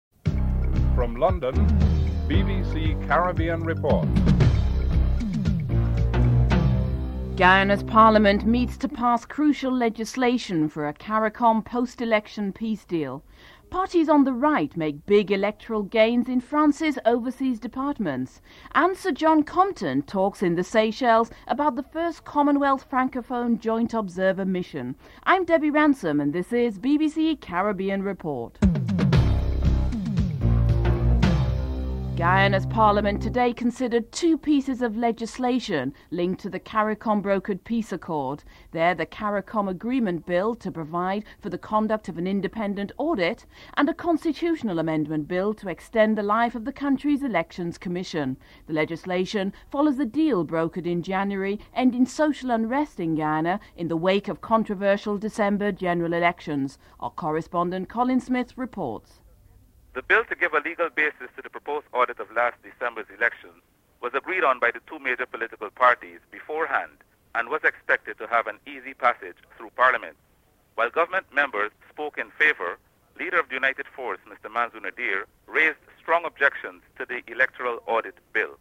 7. Former British Governor to Montserrat, Frank Savage takes up the job as the new Governor for the British Virgin Islands in July. Savage comments on whether it is unusual to move to another site in the region for his posting (12:04-15:15)